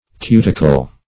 Cuticle \Cu"ti*cle\ (k[=u]"t[i^]*k'l), n. [L. cuticula, dim. of